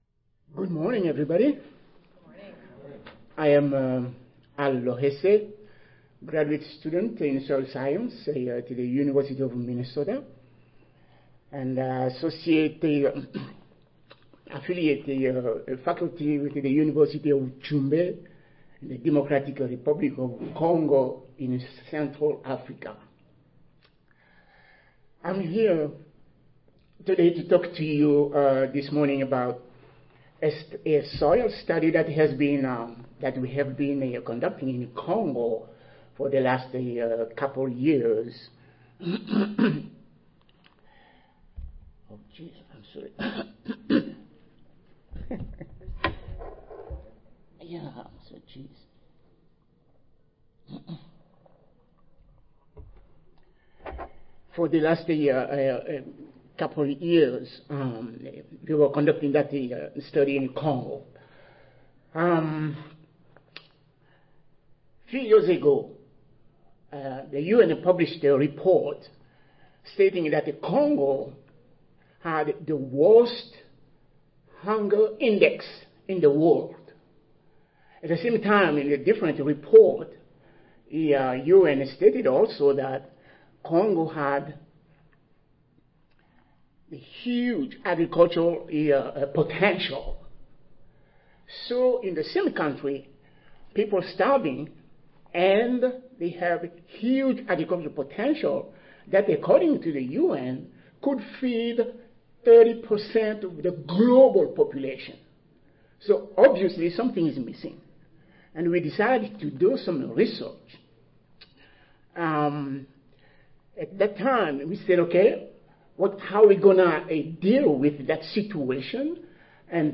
Session: Pedology General Oral (ASA, CSSA and SSSA International Annual Meetings)
University of Minnesota Audio File Recorded Presentation